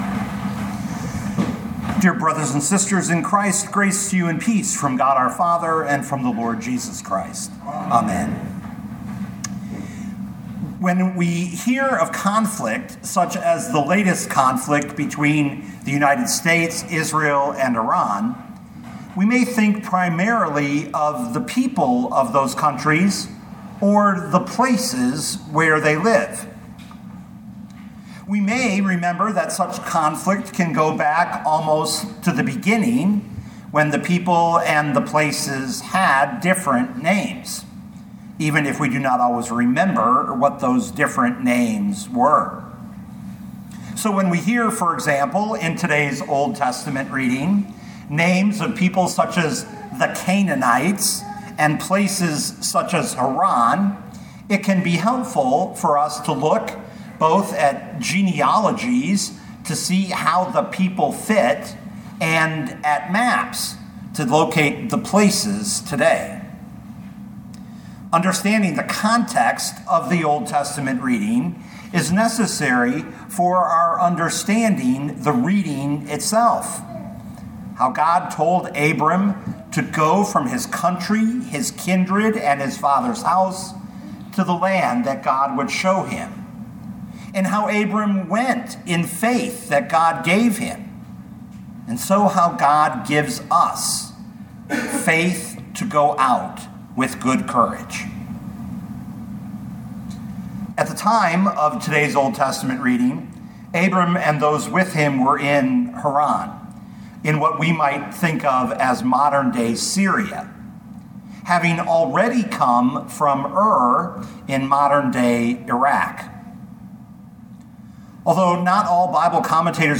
2026 Genesis 12:1-9 Listen to the sermon with the player below, or, download the audio.